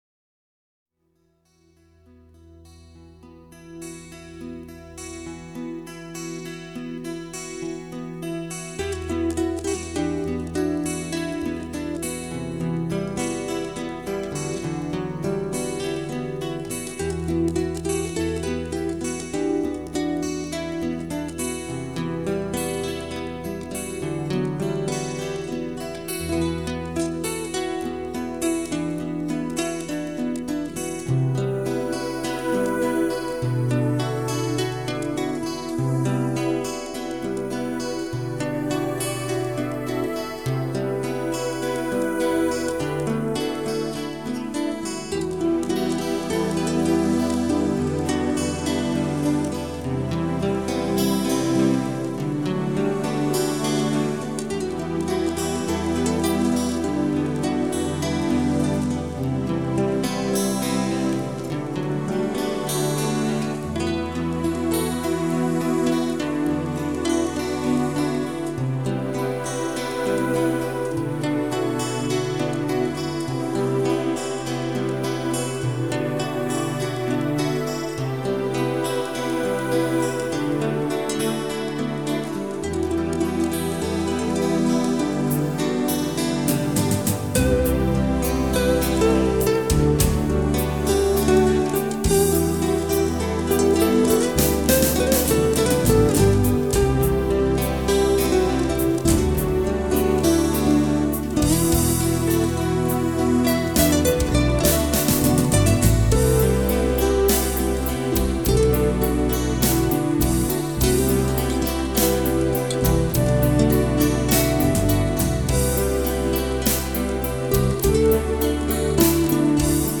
a cominciare dalle influenze folk/celtiche e mediterranee.